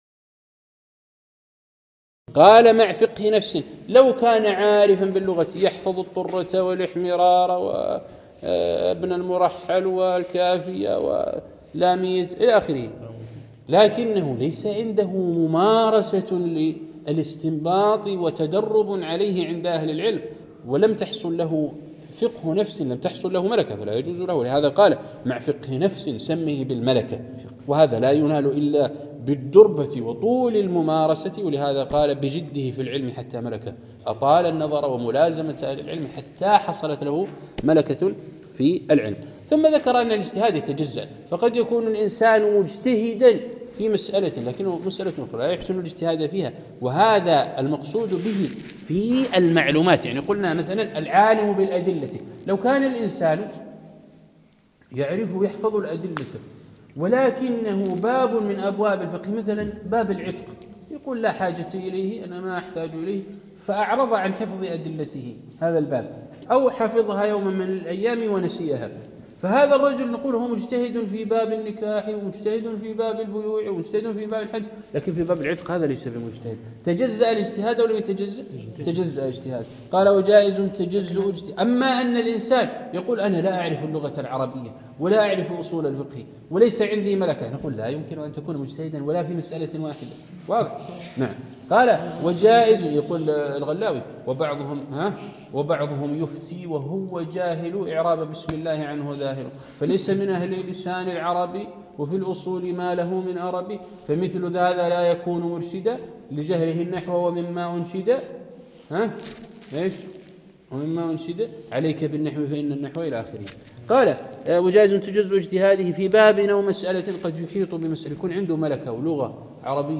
عنوان المادة الدرس (29) شرح النظم المعسول في تعليم الأصول